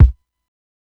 KICK_BASTARD.wav